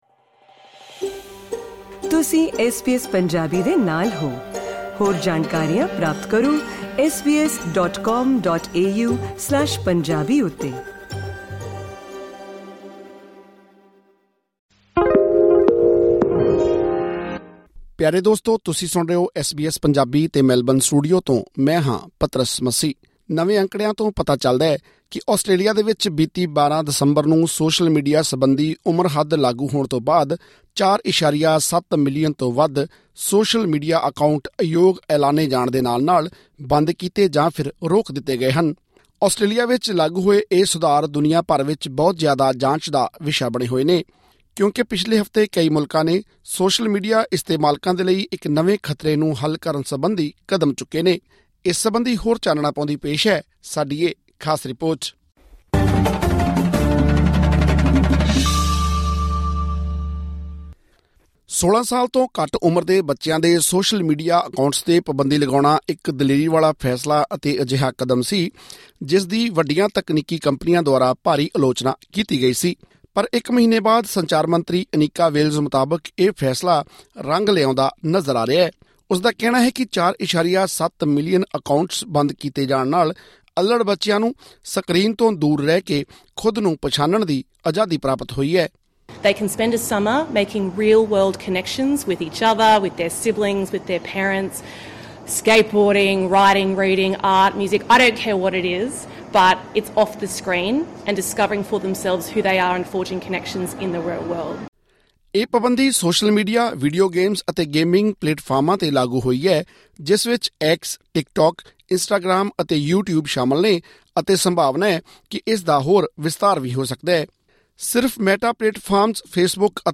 ਮਲੇਸ਼ੀਆ ਤੇ ਨਿਊਜ਼ੀਲੈਂਡ ਵਾਂਗ ਹੋਰ ਮੁਲਕ ਵੀ ਇਸੇ ਤਰ੍ਹਾਂ ਦੀਆਂ ਪਾਬੰਦੀਆਂ ਲਗਾਉਣ ਬਾਰੇ ਵਿਚਾਰ ਕਰ ਰਹੇ ਹਨ। ਹੋਰ ਵੇਰਵੇ ਲਈ ਸੁਣੋ ਇਹ ਰਿਪੋਰਟ...